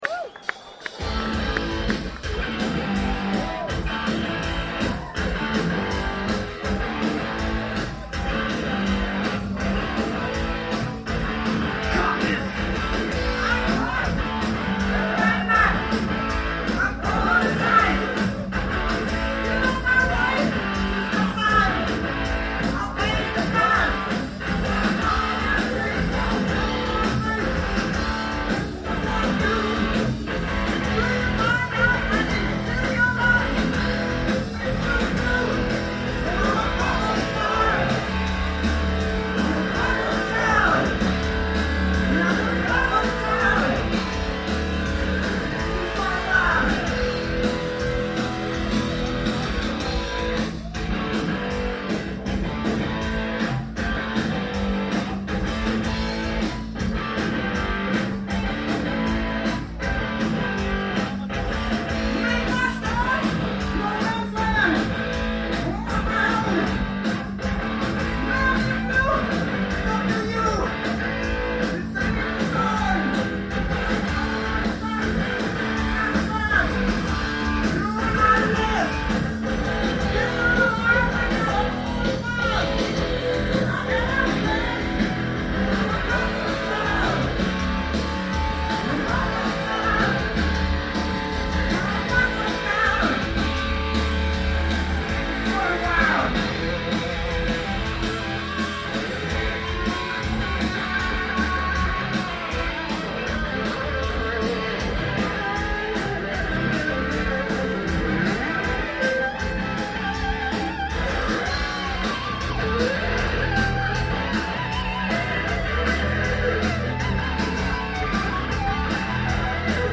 c'est une reprise classique, pas terrible.